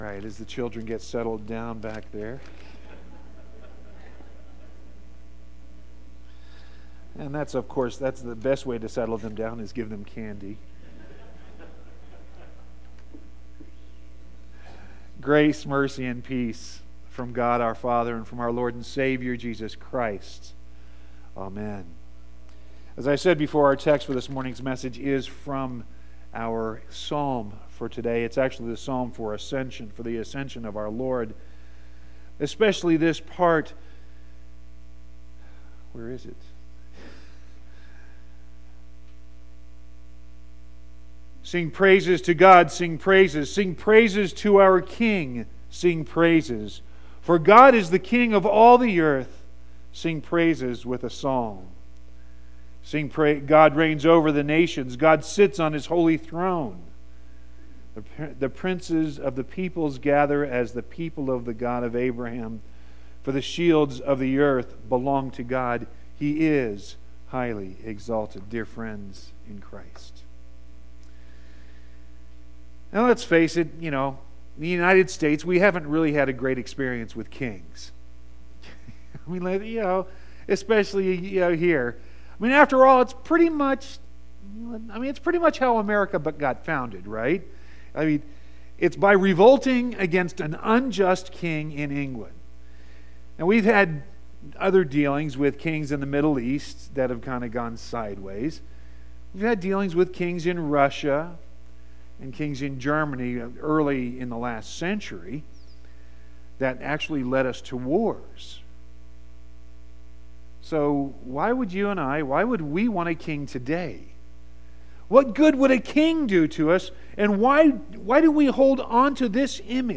5-13-18-sermon.mp3